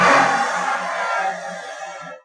saw_end2.wav